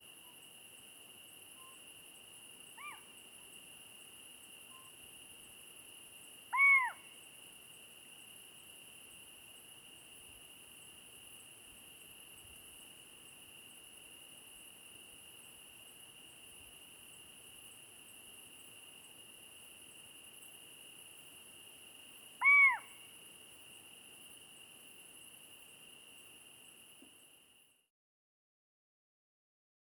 Otus cyprius
Excitement calls of a pair near their nest in an ancient olive tree. 130614.
The one giving the very first call is slightly higher-pitched than the other that calls from then onwards.
2-34-Cyprus-Scops-Owl-Excitement-calls-of-pair-near-nest-in-ancient-olive-tree.wav